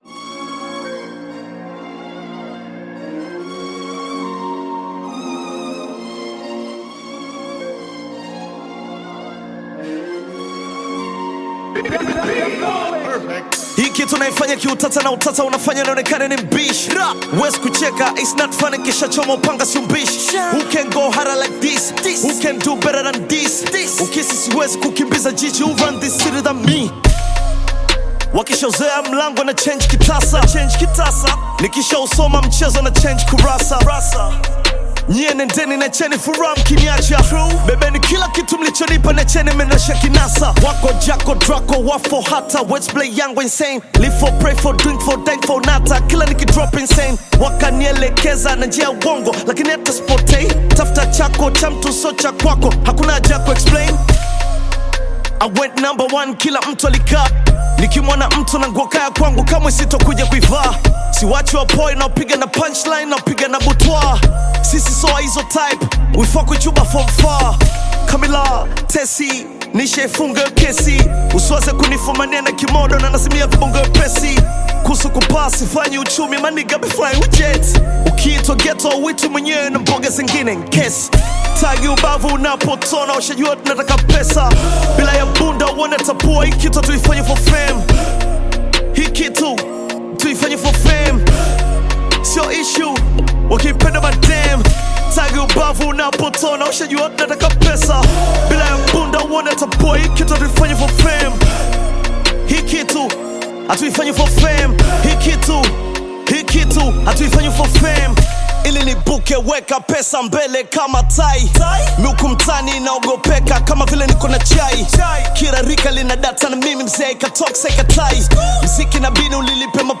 Tanzanian Bongo Flava artist, singer, and songwriter
Bongo Flava You may also like